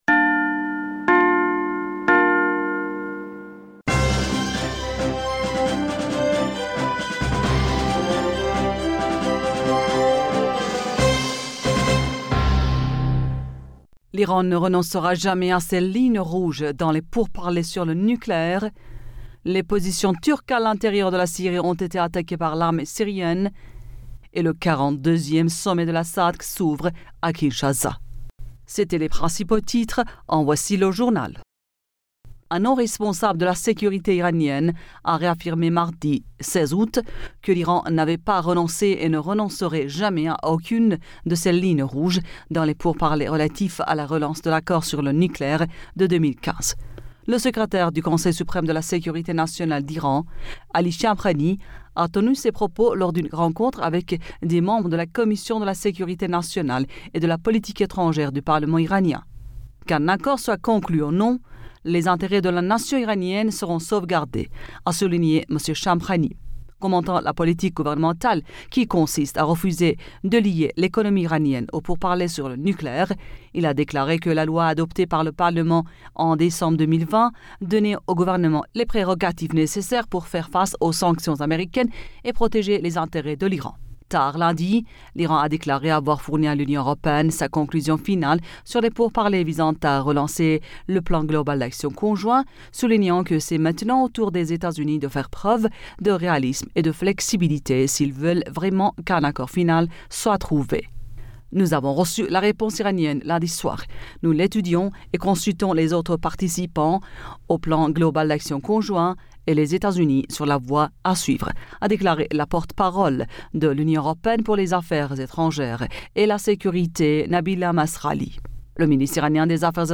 Bulletin d'information Du 17 Aoùt